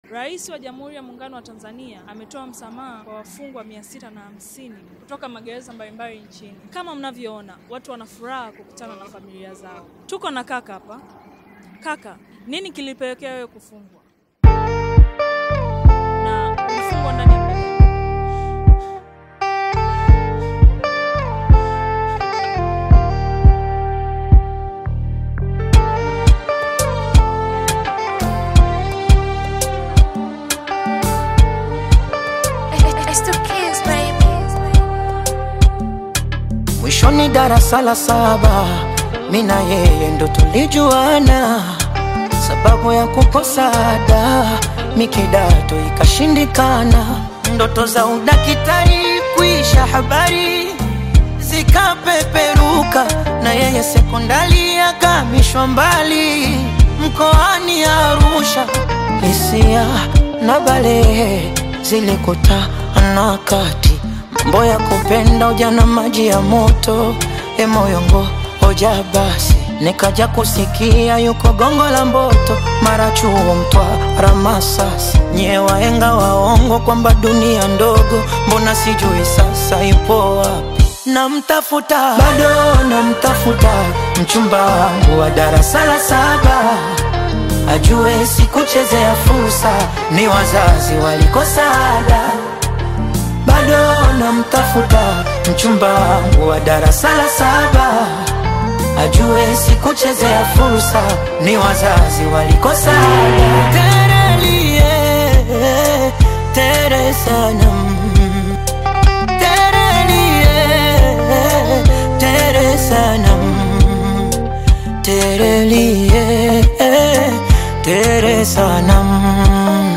” a deeply emotional track inspired by first love